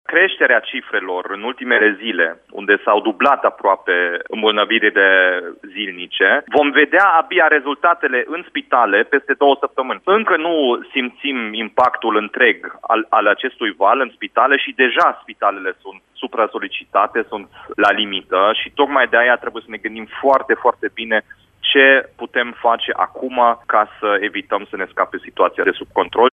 Primarul Timișoarei a declarat astăzi, în direct la Radio Timișoara, că ne aflăm, deja, în valul trei al pandemiei, iar tulpina britanică a virusului se răspândește în comunitate.